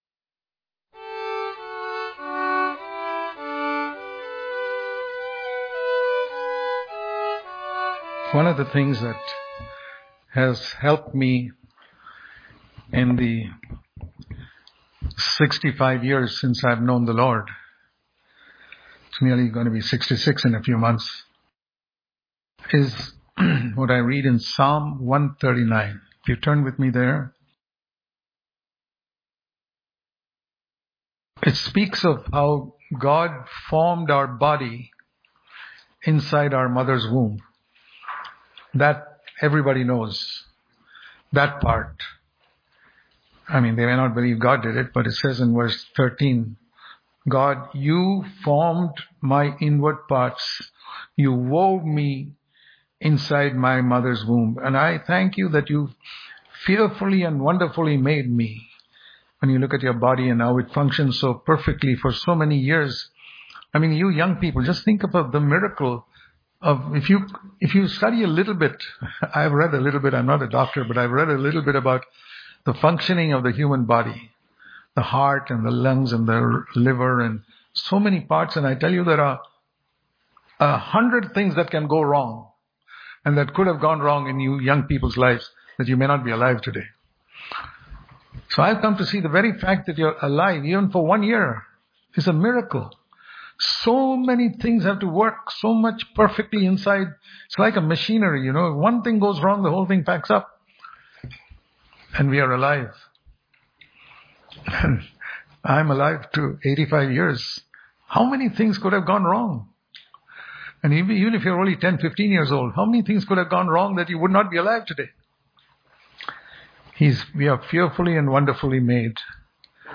August 24 | Daily Devotion | Completing God’s Plan For Our Lives Daily Devotion